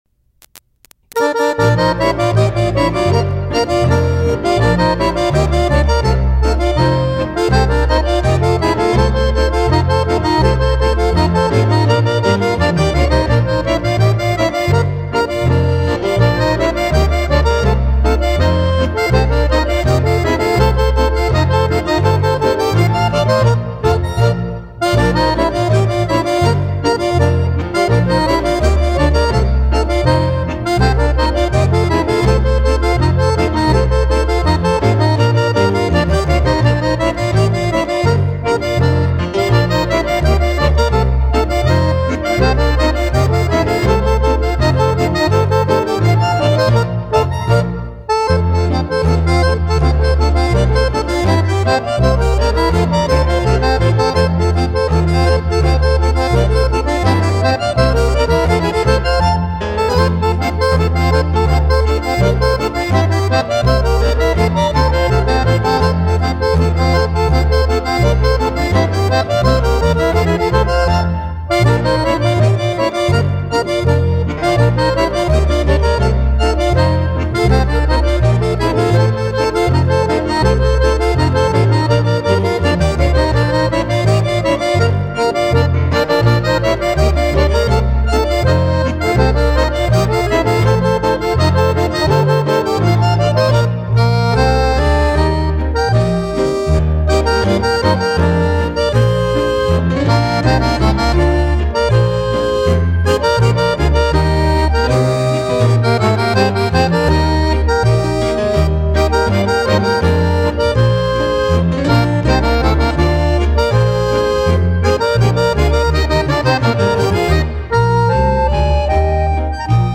Akkordeonist